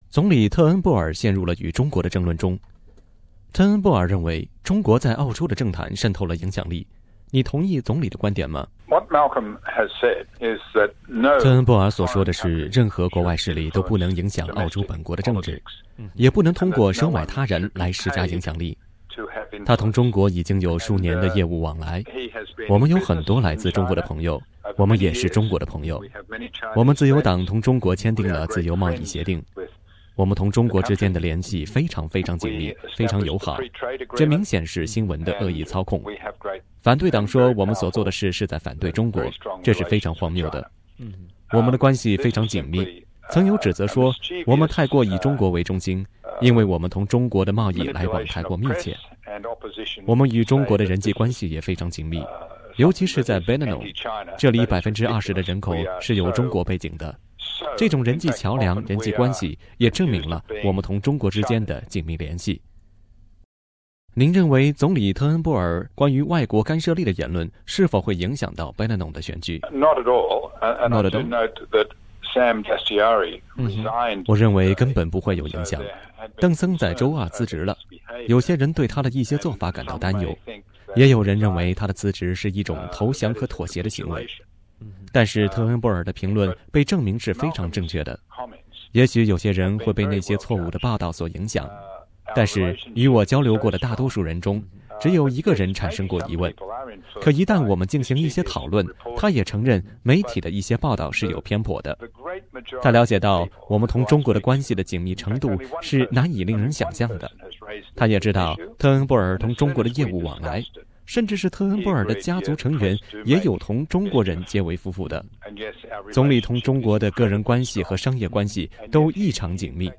中澳关系将持续稳定——采访自由党候选人John Alexander